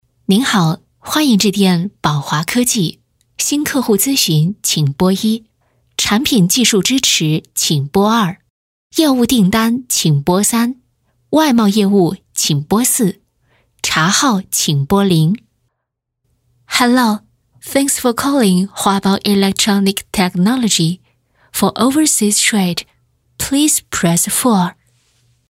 定制彩铃-女21-双语彩铃.mp3